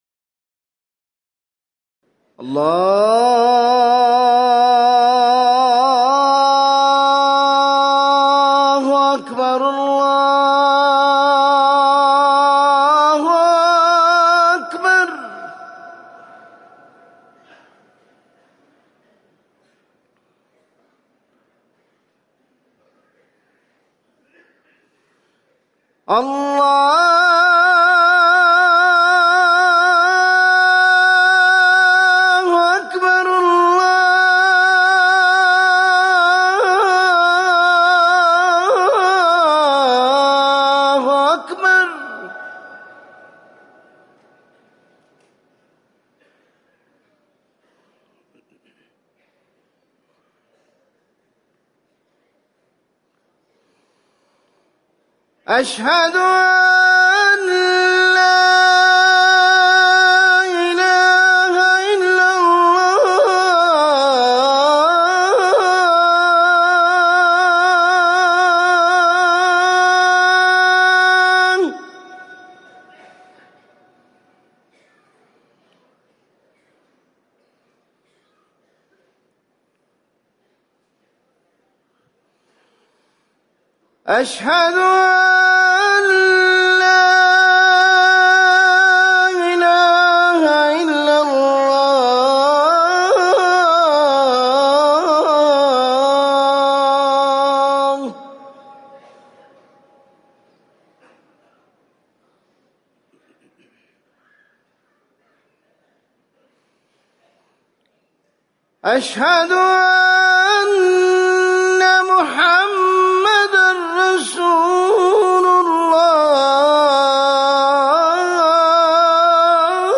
أذان الفجر الأول - الموقع الرسمي لرئاسة الشؤون الدينية بالمسجد النبوي والمسجد الحرام
تاريخ النشر ٩ محرم ١٤٤١ هـ المكان: المسجد النبوي الشيخ